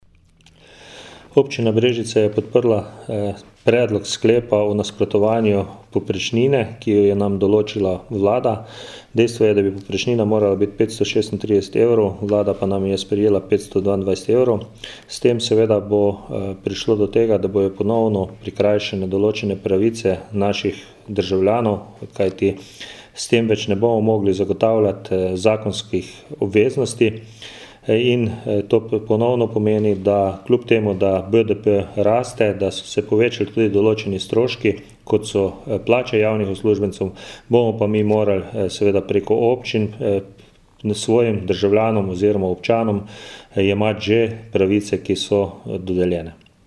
Izjava župana Brežic, Ivana Molana
zupan-obcine-brezice-ivan-molan-o-podpori-sklepu-s-katerim-obcine-nasprotujejo-znizanju-povprecine.mp3